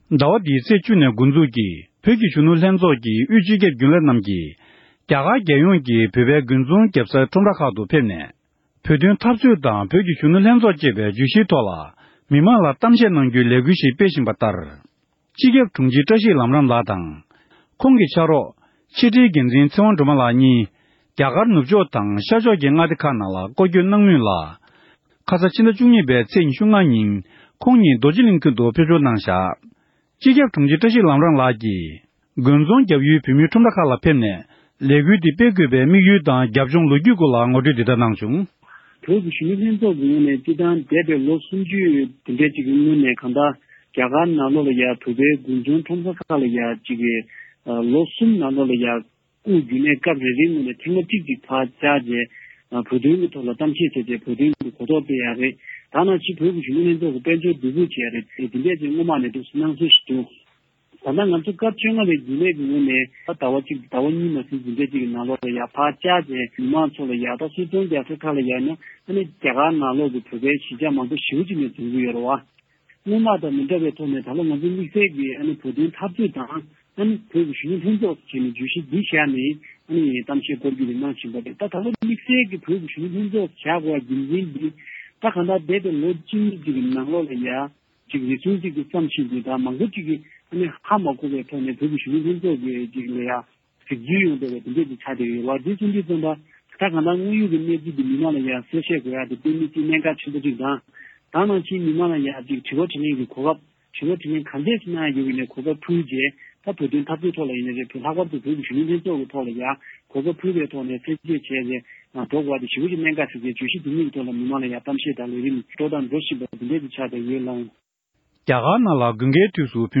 གསར་འགྱུར་དང་འབྲེལ་བའི་ལེ་ཚན་ནང་།